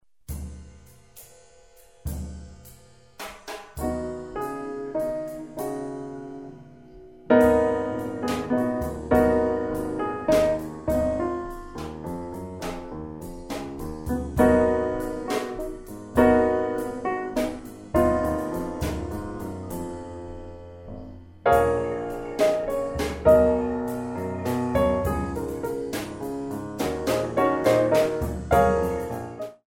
3/4  mm=104